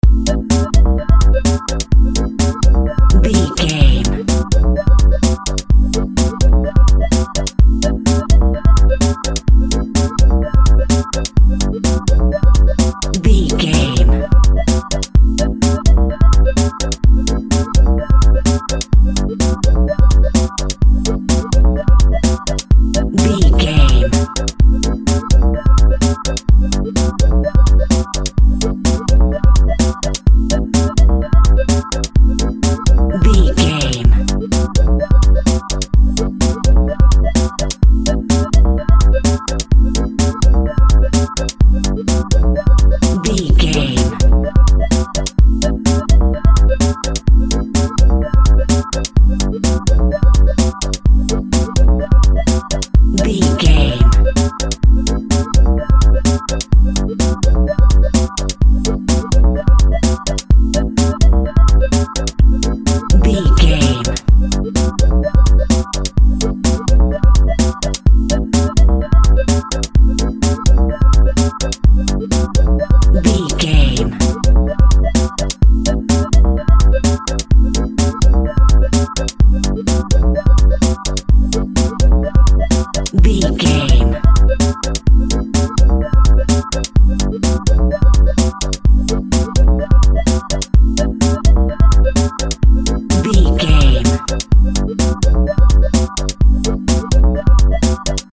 Chilling Electric Music Theme.
Aeolian/Minor
groovy
uplifting
futuristic
synthesiser
drum machine
Drum and bass
break beat
electronic
sub bass
synth lead
synth bass